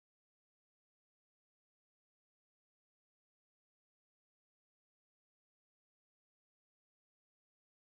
Kinderlieder: Murmeltiers Reise
Tonart: C-Dur
Taktart: 2/4
Tonumfang: Quarte
Besetzung: vokal